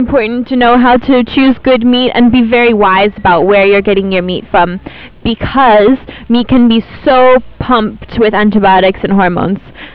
The attached sound file is brute force patching. I reduced the volume slightly and muffled everything with Low Pass Filter. At those settings, the crisp, crunchy goes away, but it turns the interview into a moderate quality phone call.
Effect > Normalize: Normalize to -2dB
Effect > Low Pass Filter: 48dB, 3461